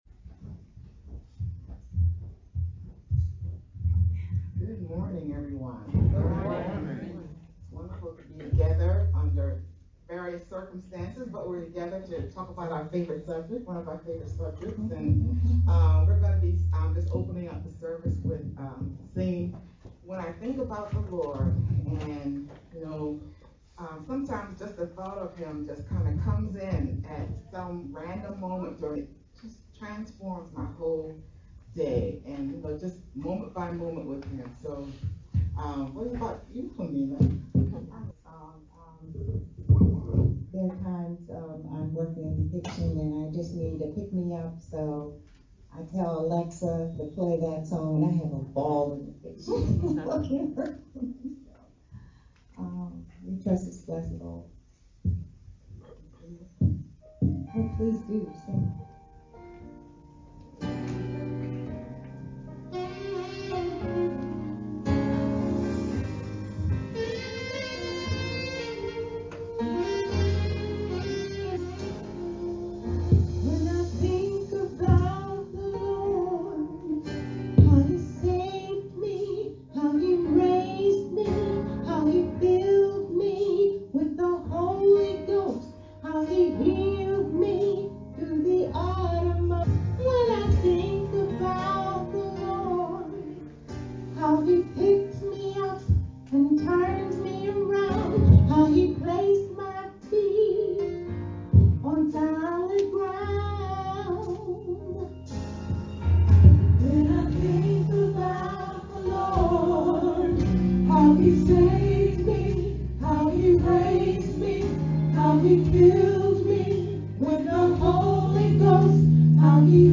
Oct-17-VBCC-edited-Sermon-Mp3-CD.mp3